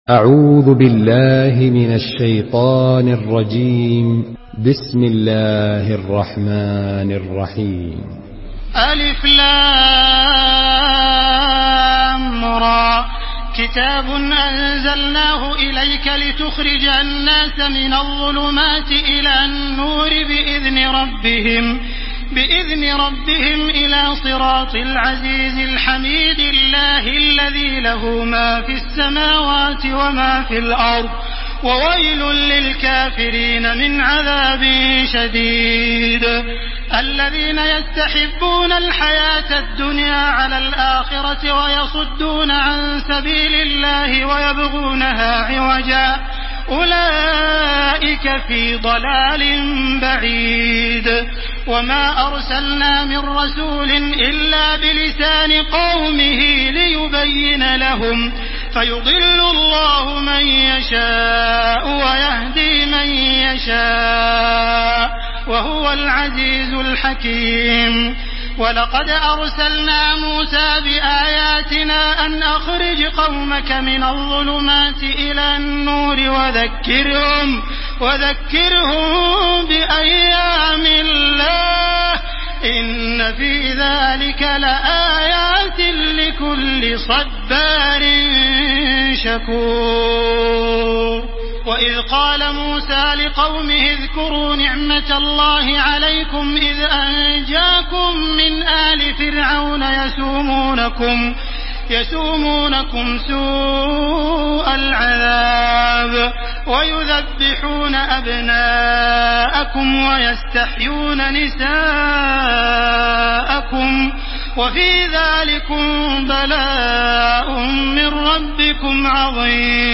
Surah Ibrahim MP3 by Makkah Taraweeh 1431 in Hafs An Asim narration.